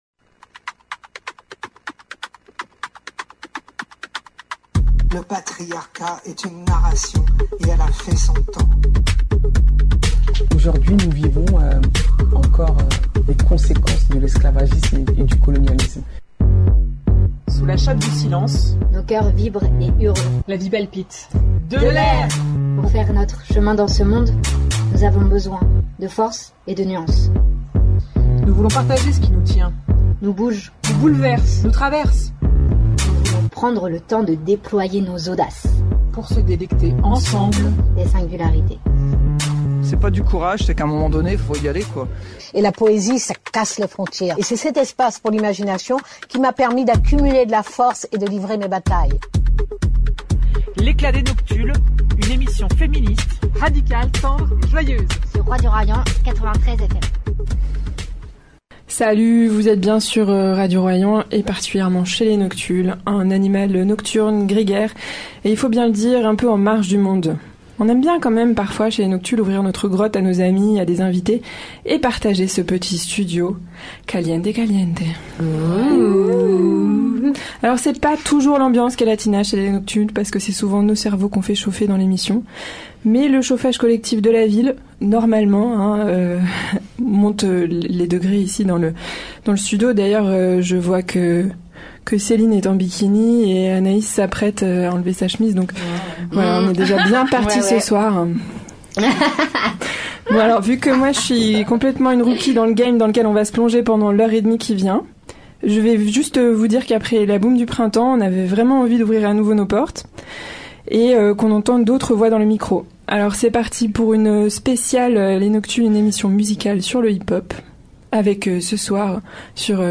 Chez les noctules, animal nocturne, grégaire et il faut bien le dire un peu en marge du monde, on aime bien quand même parfois ouvrir notre grotte à nos ami.e.s, à des invité.e.s et partager ce petit studio caliente-caliente.